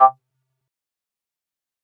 Звуки пульта от телевизора